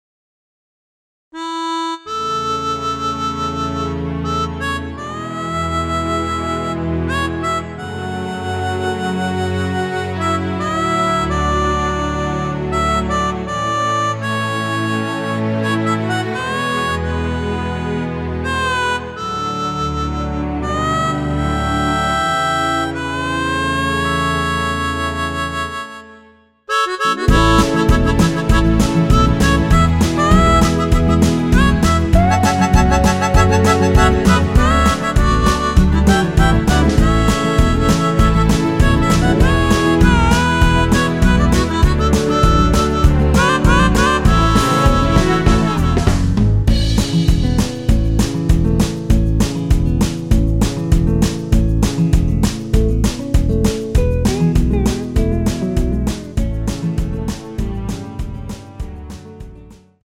원키에서(-3)내린 MR입니다.
앞부분30초, 뒷부분30초씩 편집해서 올려 드리고 있습니다.